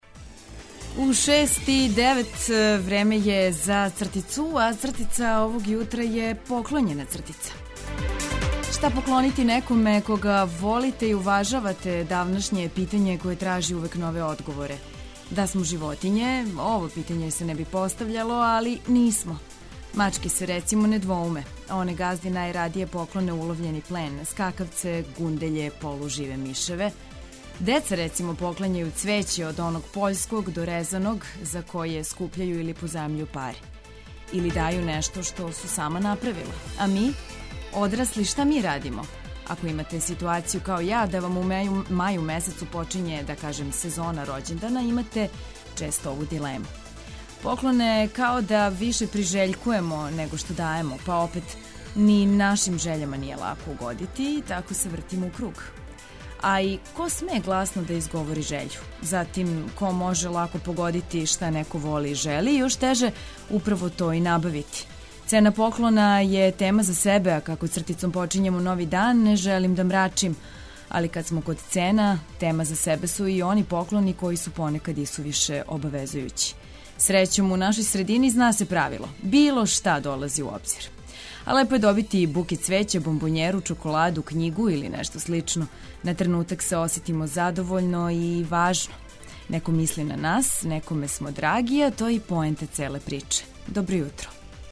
Због тога ујутру емитујемо музику за размрдавање, а размрдати може и звук "ћирилице".